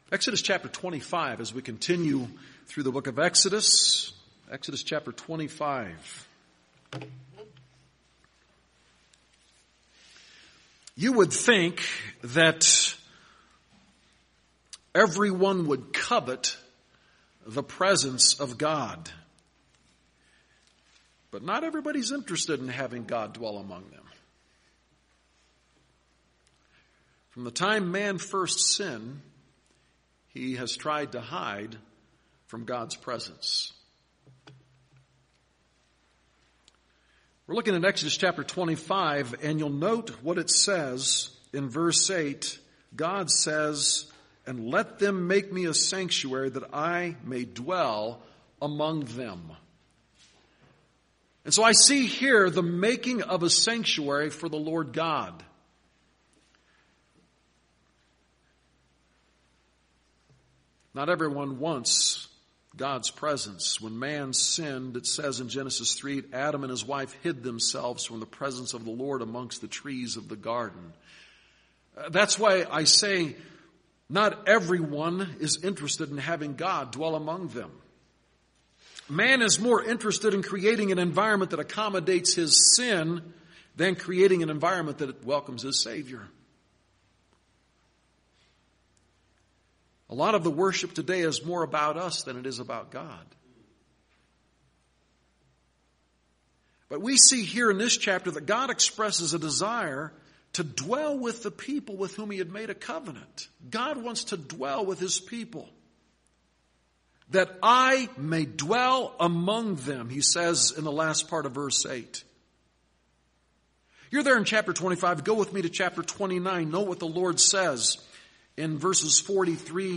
Sermons | Westside Baptist Church - Greeley, CO